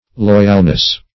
loyalness - definition of loyalness - synonyms, pronunciation, spelling from Free Dictionary Search Result for " loyalness" : The Collaborative International Dictionary of English v.0.48: Loyalness \Loy"al*ness\, n. Loyalty.